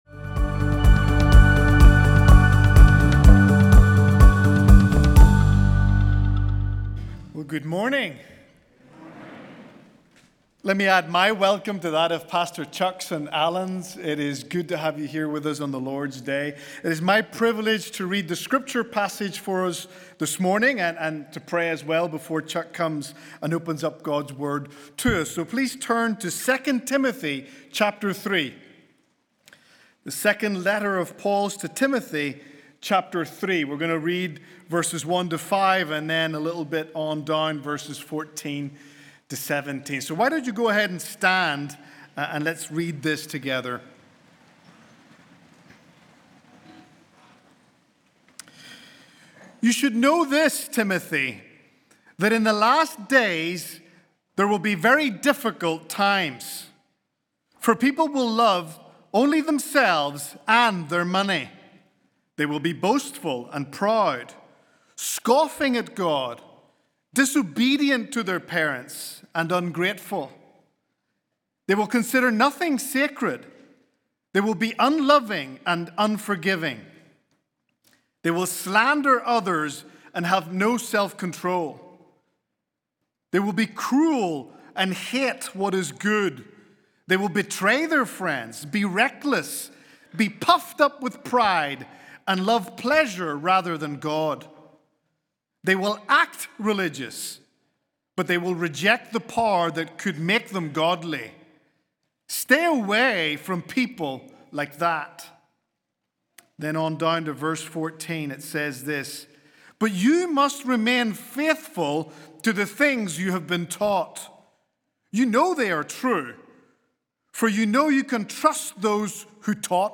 Will you stand for Christ when no one else will? Will you hold to the truth when tempted by lies? Tune in as Pastor Chuck Swindoll continues to guide us through the wisdom and warnings of 2 Timothy…